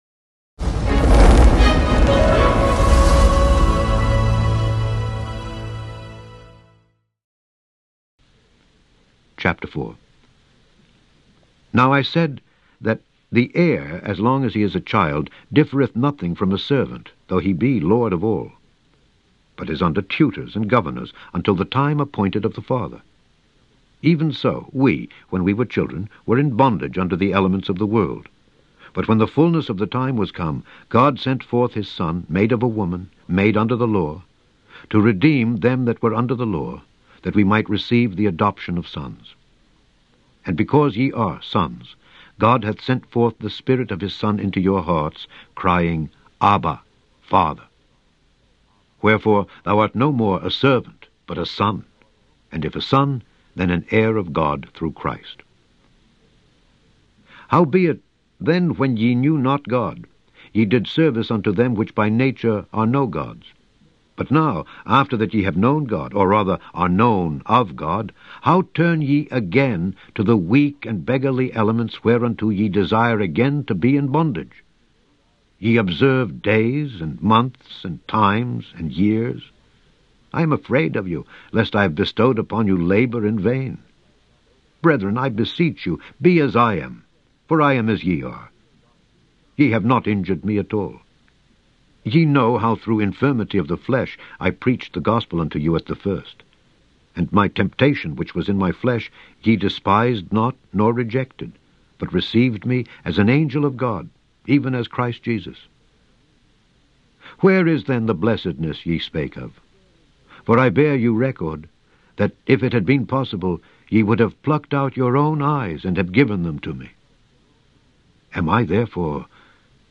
Daily Bible Reading: Galatians 4-6
In this podcast, you can listen to Alexander Scourby read Galatians 4-6 to you.